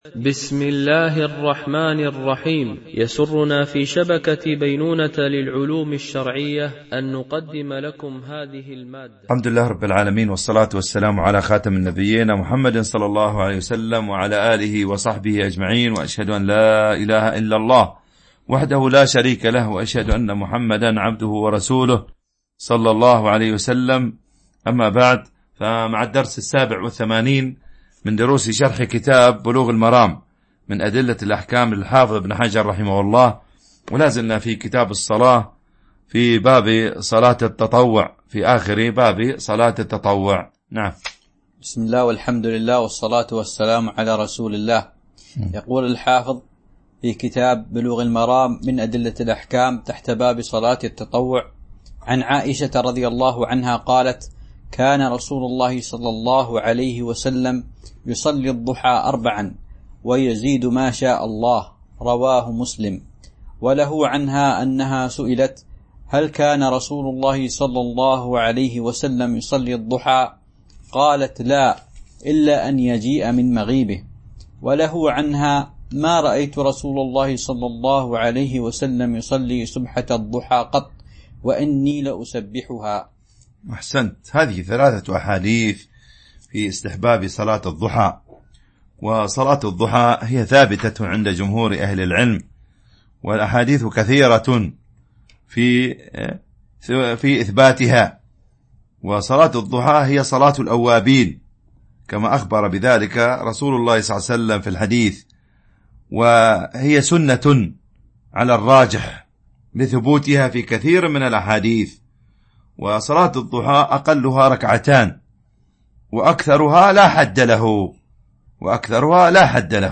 شرح بلوغ المرام من أدلة الأحكام - الدرس 87 ( كتاب الصلاة - باب صلاة التطوع , الحديث 391- 396 )